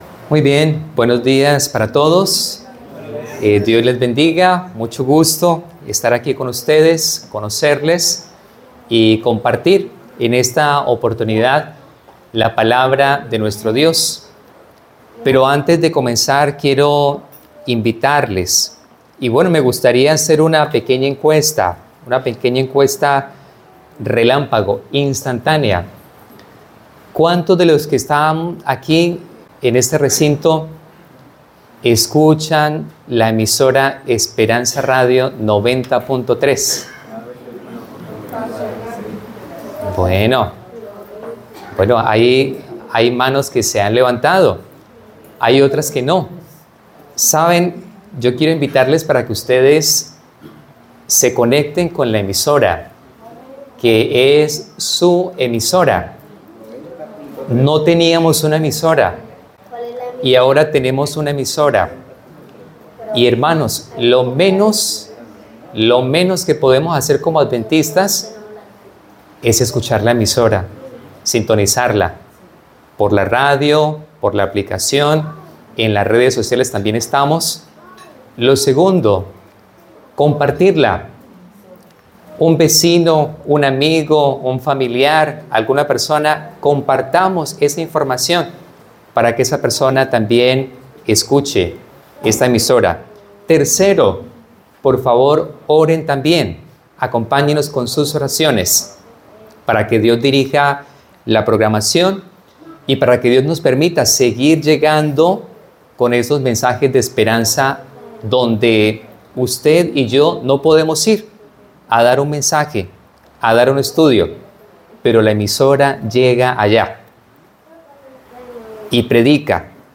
Predicaciones Segundo Semestre 2025